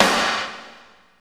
53.10 SNR.wav